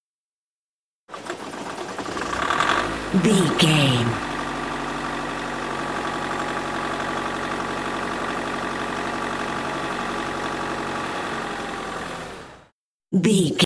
Van cargo start idle
Sound Effects